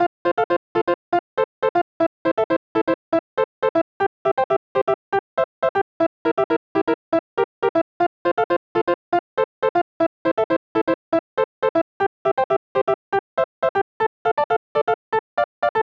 リリースカットピアノのおしゃれなコードが決め手！
BPM：120 キー：F ジャンル：あかるい、おしゃれ 楽器：リリースカットピアノ